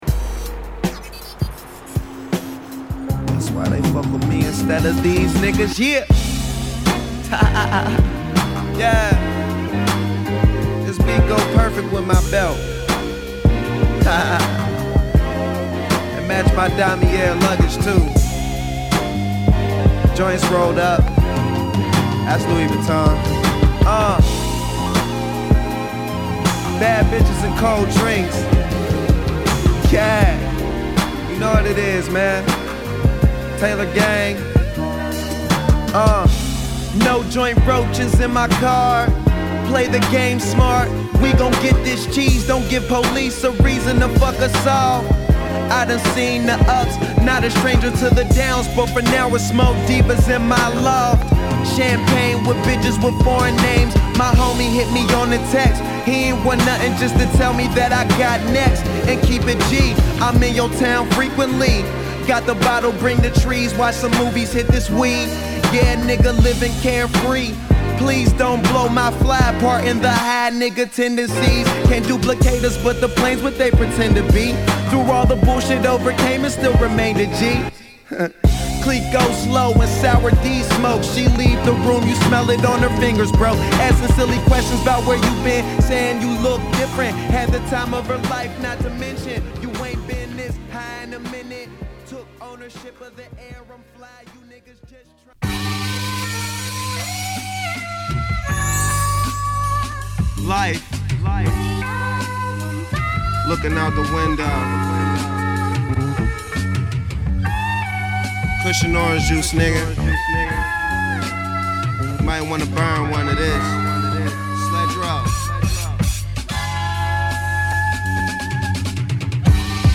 打ち込みトラックからサンプリング・ビーツまでバランス良く配したビート群に乗せ、クールなストーナーラップを披露する